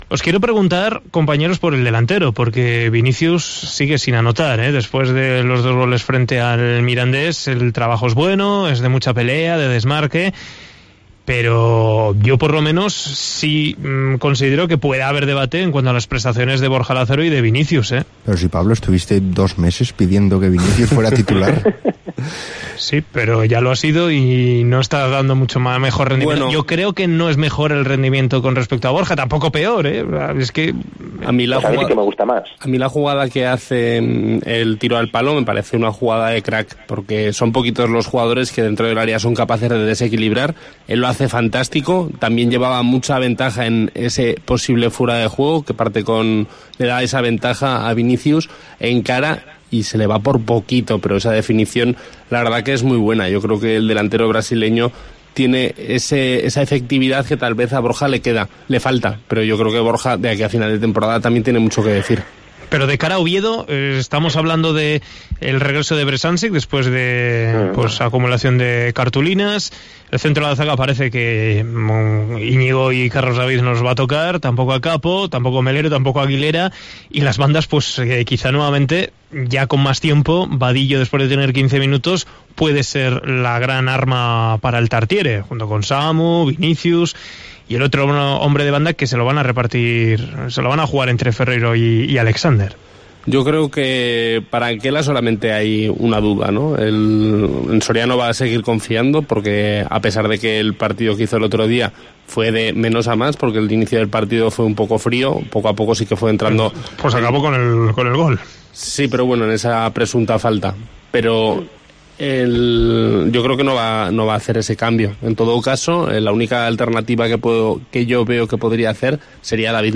Con nuestra mesa de comentaristas hablamos de la punta de ataque del Huesca y comentamos si habrá cambios en el once inicial por el que Anquela apostará en Oviedo.